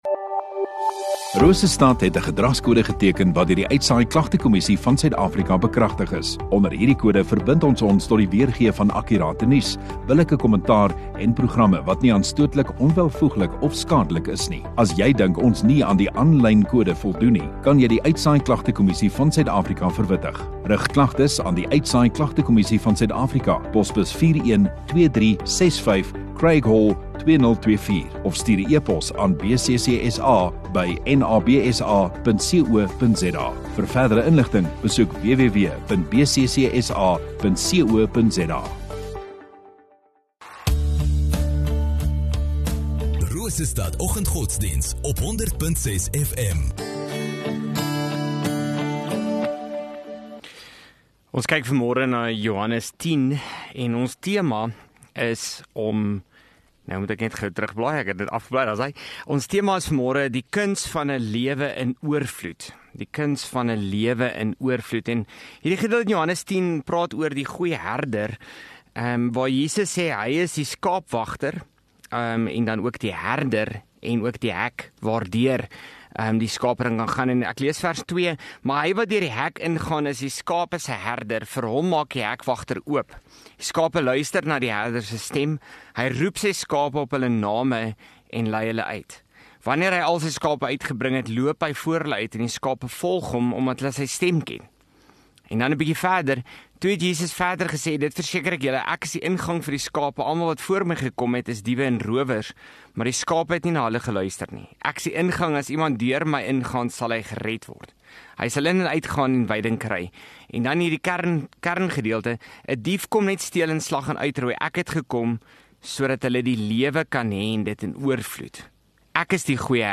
Rosestad Godsdiens